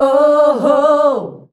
OOOHOO  D.wav